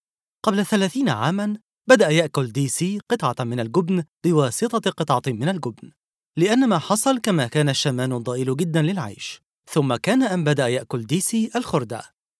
Mısır Arapçası Seslendirme
Erkek Ses